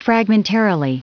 Prononciation du mot fragmentarily en anglais (fichier audio)
Prononciation du mot : fragmentarily
fragmentarily.wav